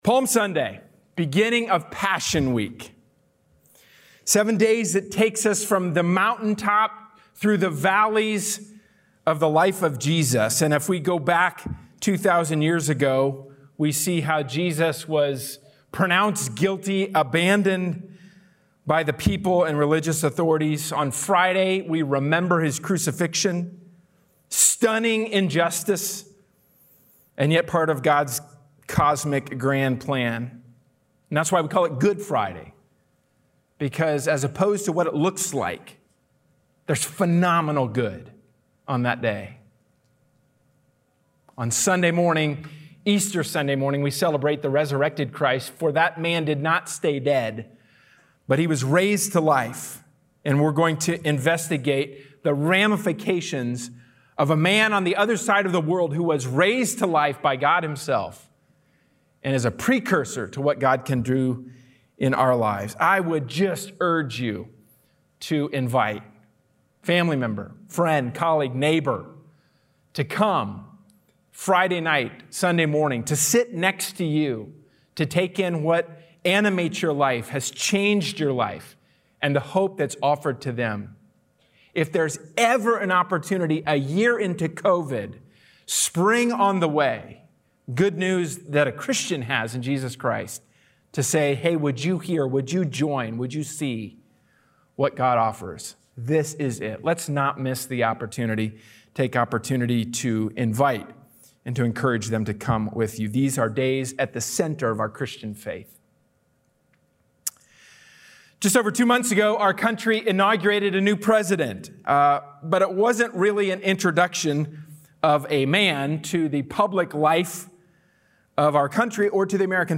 A sermon from the series "A New Start."